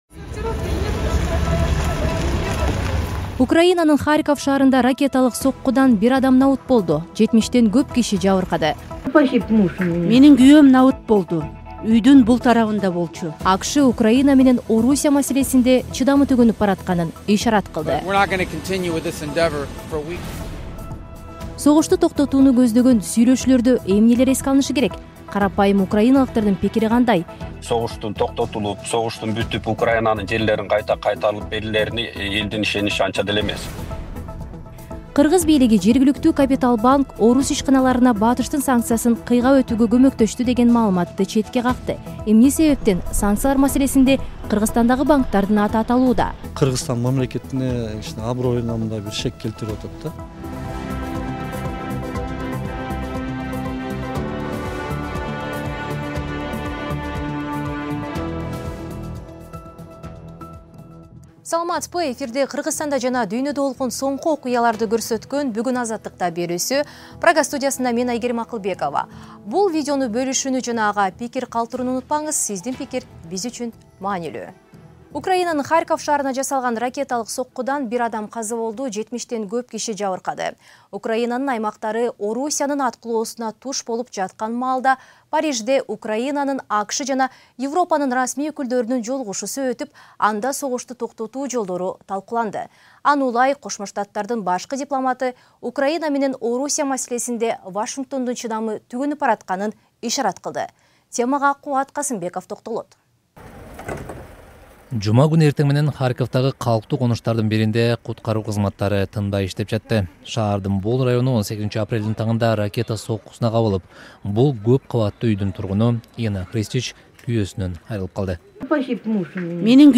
Жаңылыктар | 18.04.2025 | Украинанын келечеги: Киевдеги кыргыздар эмне дейт?
Киевдеги кыргызстандык менен маектешебиз.